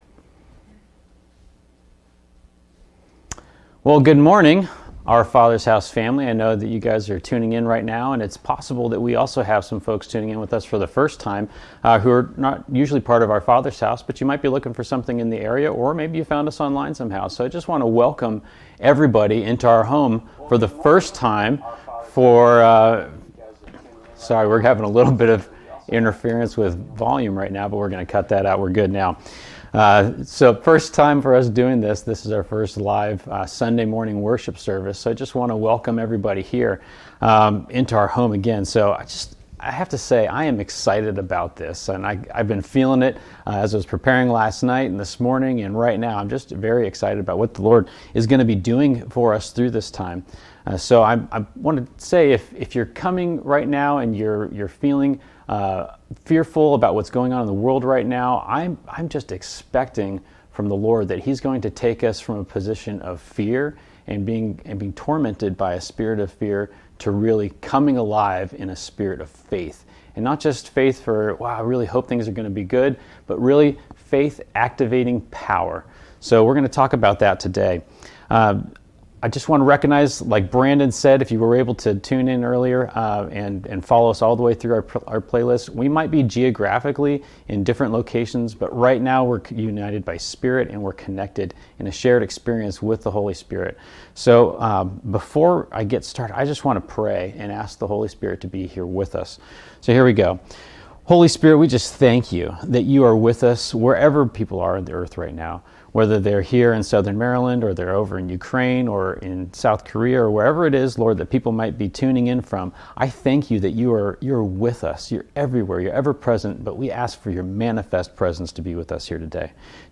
delivers our first Sunday service from home and encourages us to enter into the Lord's rest and to move powerfully from a position of faith rather than fear. Join us as we engage God in the incredible opportunity He has for us in this hour.